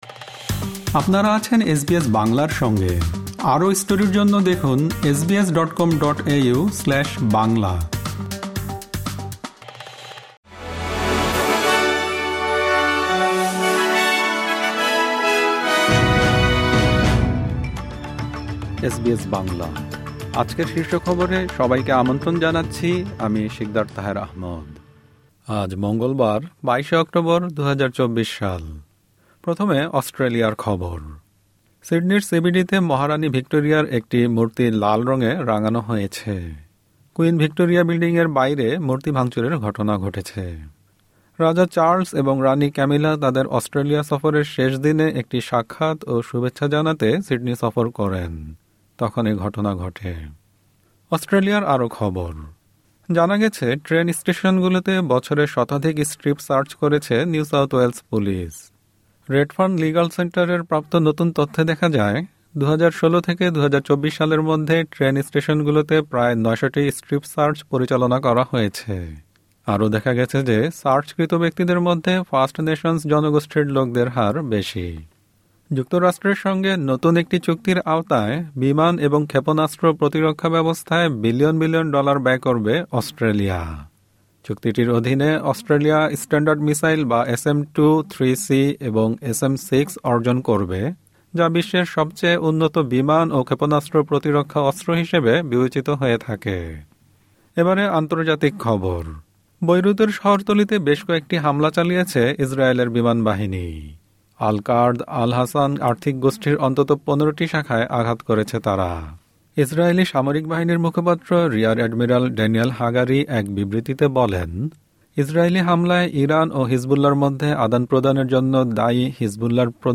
এসবিএস বাংলা শীর্ষ খবর: ২২ অক্টোবর, ২০২৪